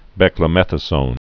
(bĕklə-mĕthə-sōn, -zōn)